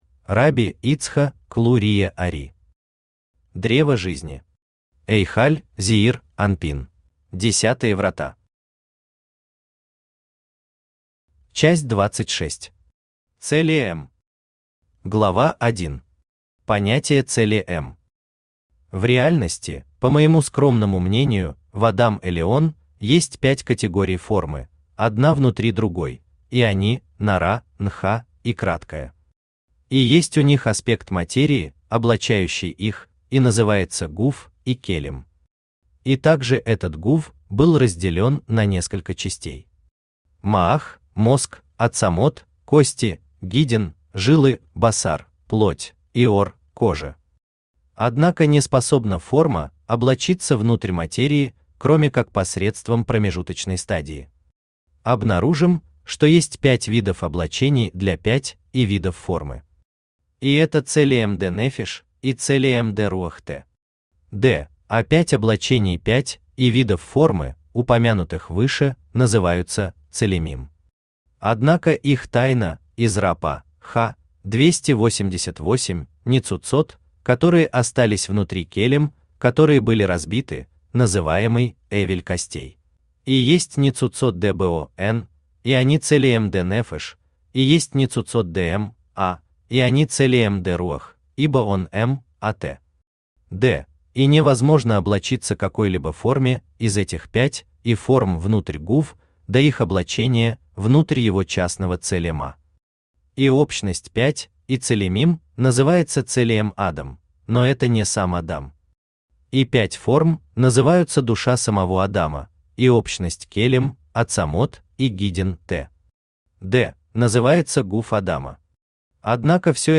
Аудиокнига Древо Жизни. Эйхаль Зеир Анпин | Библиотека аудиокниг